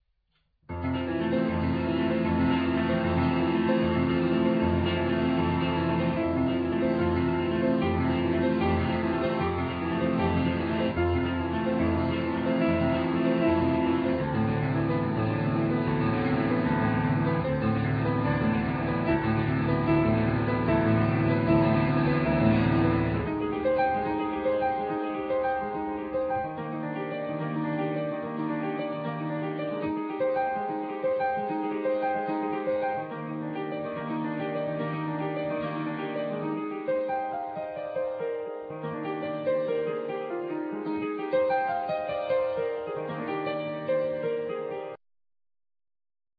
Piano(YAMAHA)